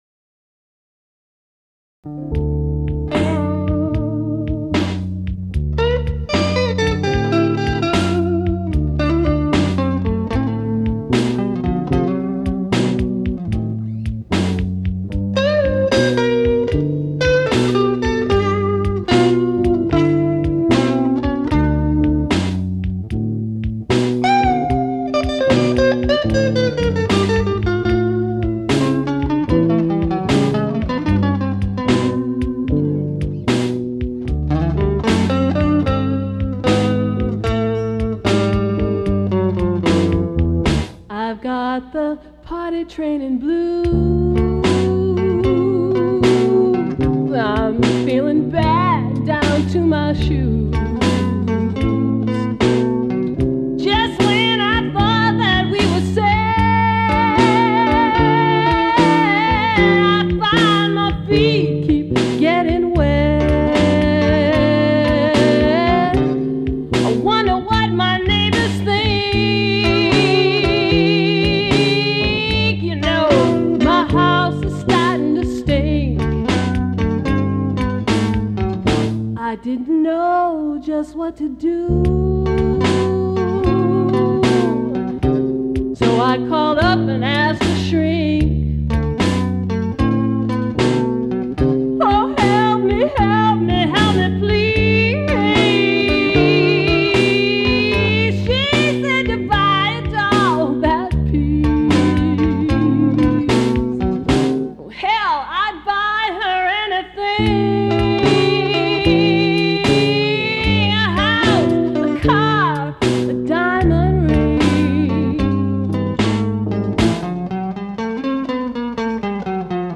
Bass/guitar/drum programming